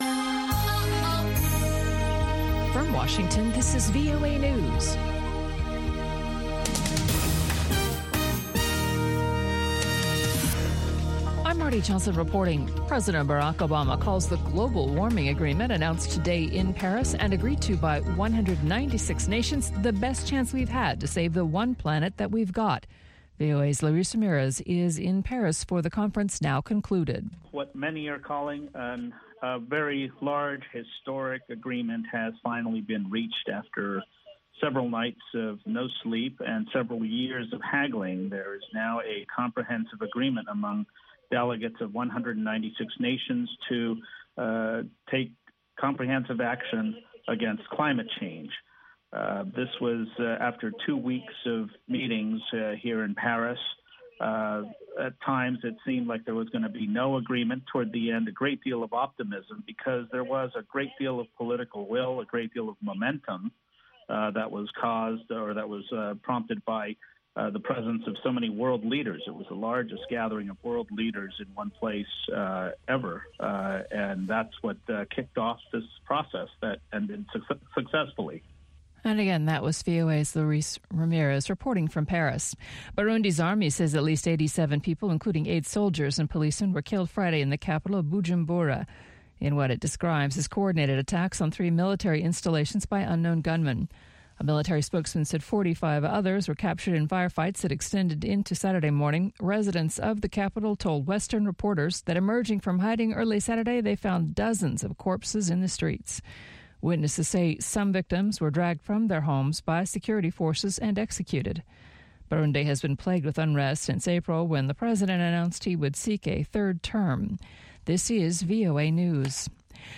from N’dombolo to Benga to African Hip Hop
pan-African music